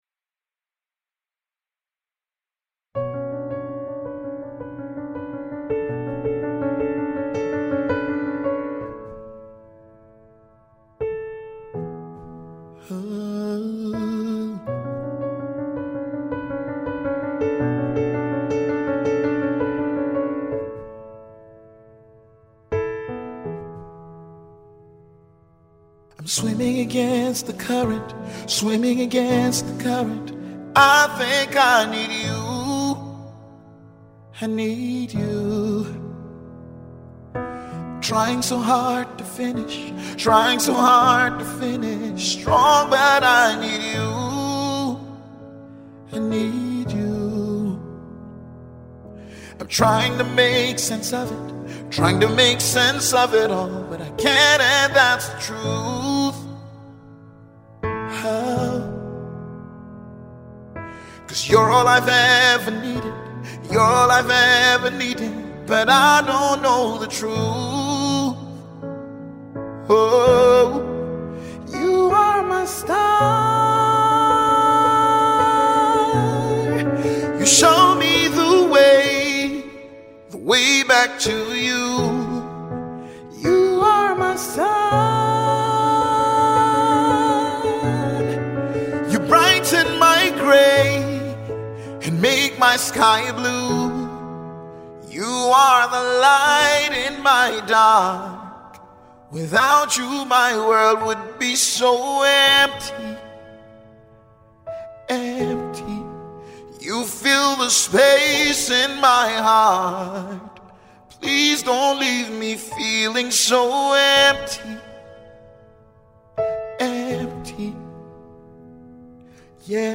serenading song